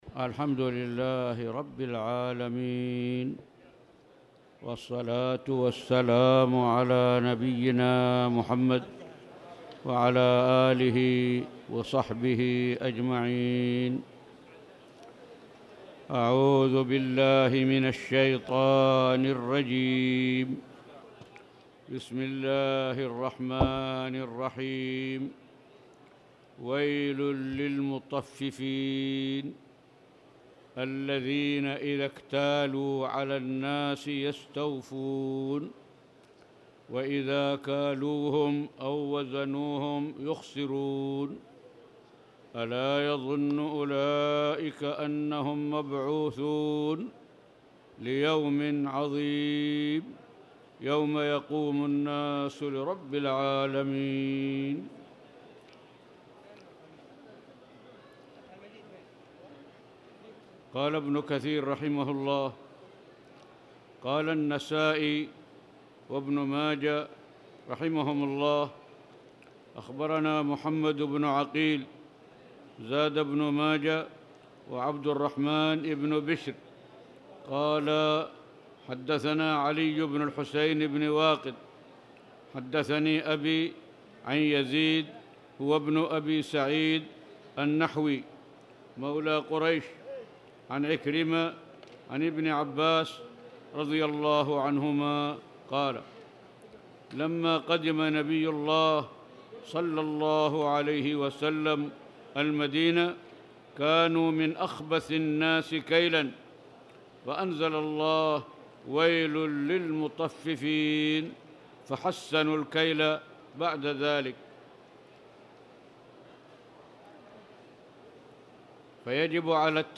تاريخ النشر ١٣ شعبان ١٤٣٧ هـ المكان: المسجد الحرام الشيخ